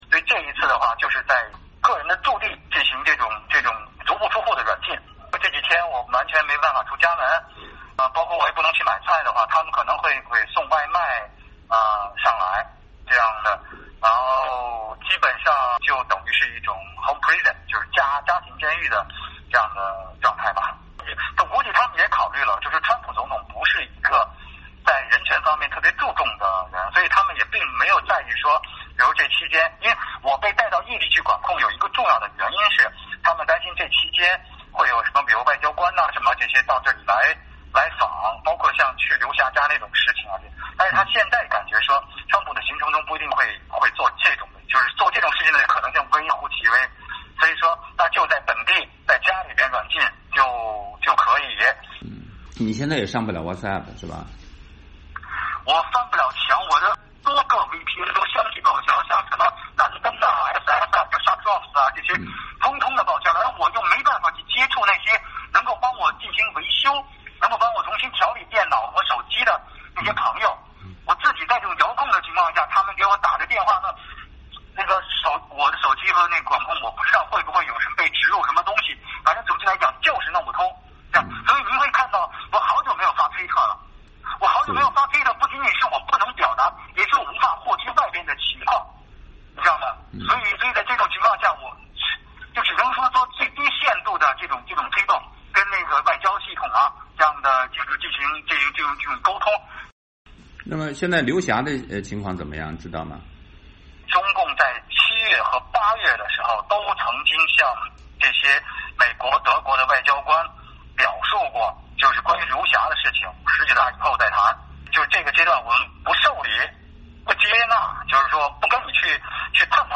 （根据电话采访录音整理。